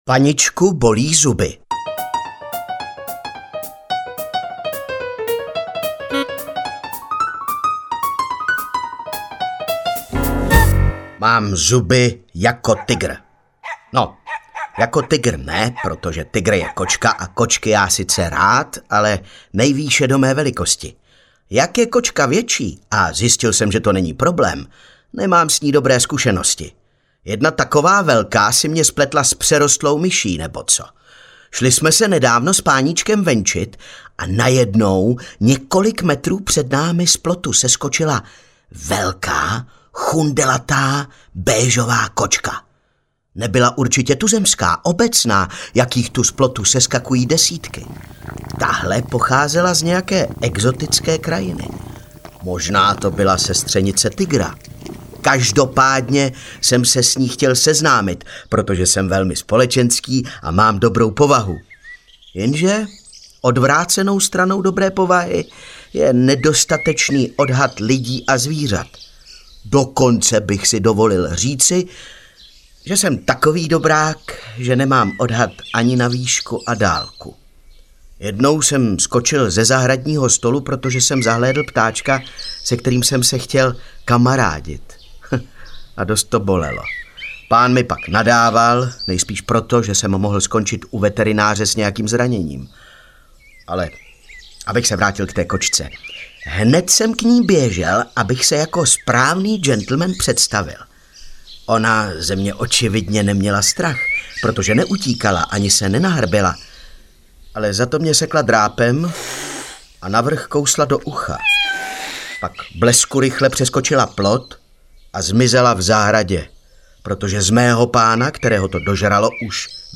Ukázka z knihy
my-psi-ze-vsi-audiokniha